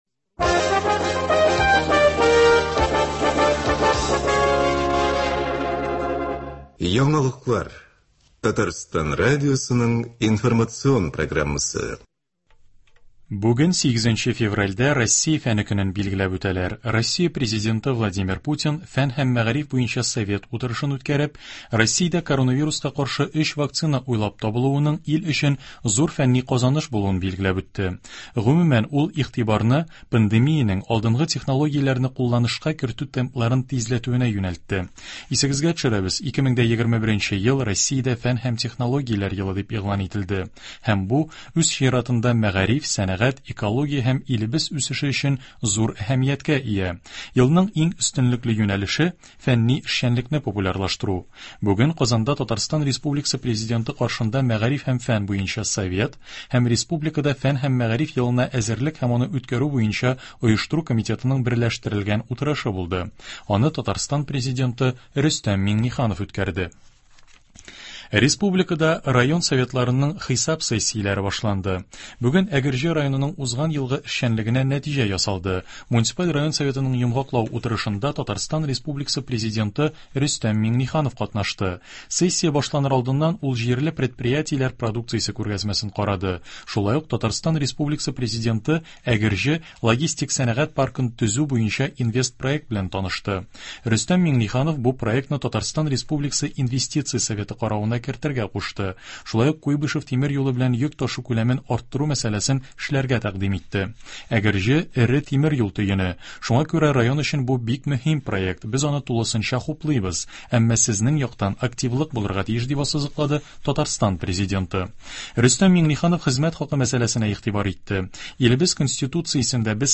Яңалыклар (08.02.21)